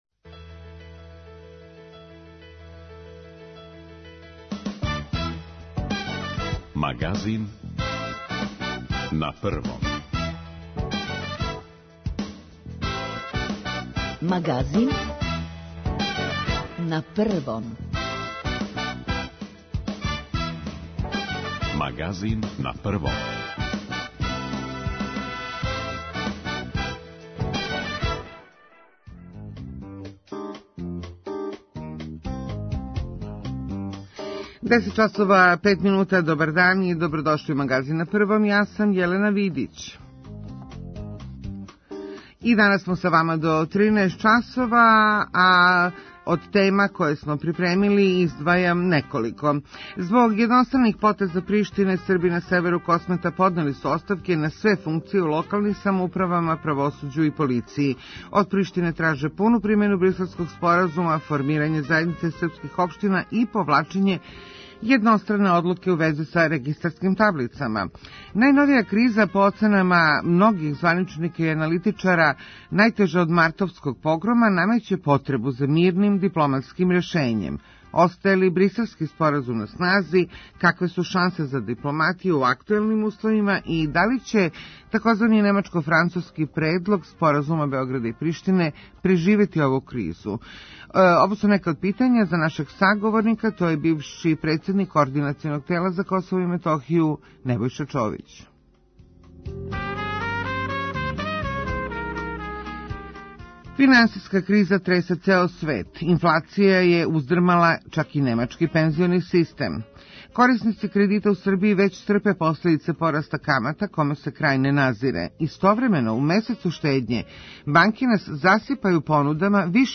Наш саговорник је бивши шеф координационог тела за Косово и Метохију Небојша Човић.